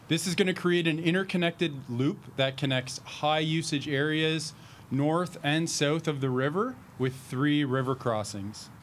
city-snow-injterview.mp3